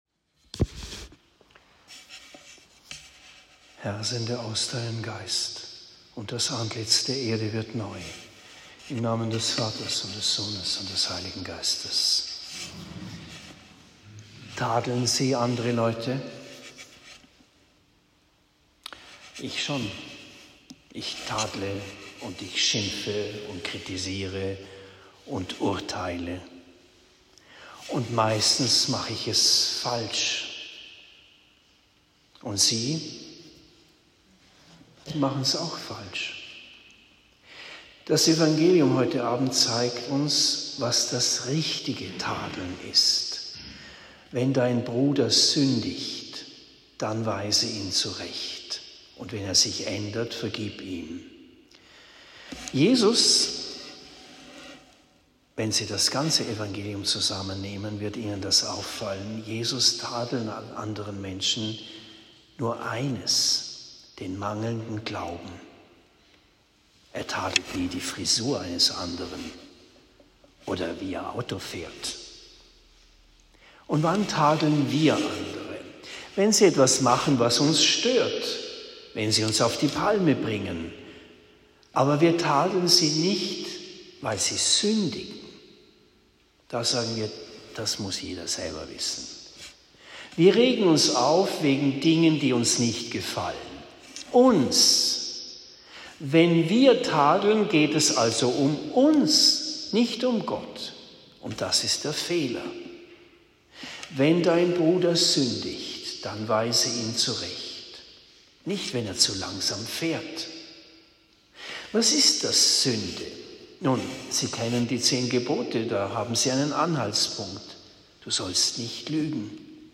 07. November 2022 – Predigt in Bischbrunn im Spessart